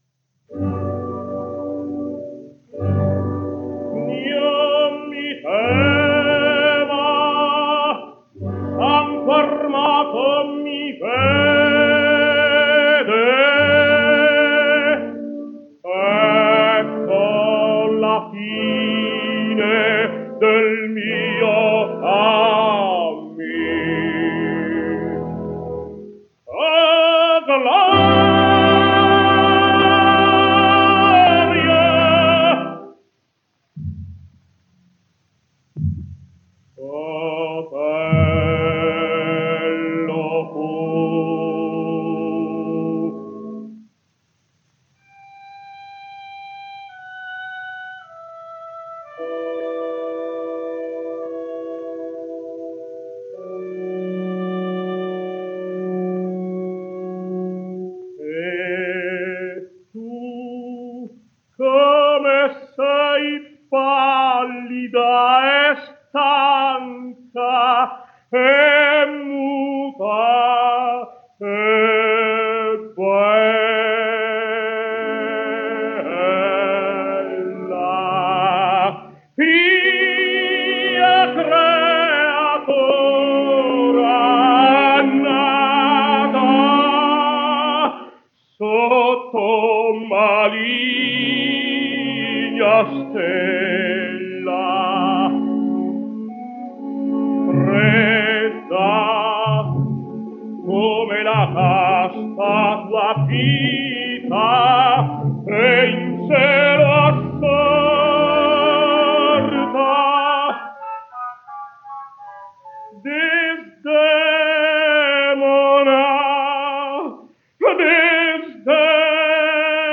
Giovanni Zenatello | Italian Tenor | 1876 - 1949 | Tenor History
And for good measure, we shall follow that with the death of Otello, taken from an electrical HMV of 1928, when Zena Tello was 52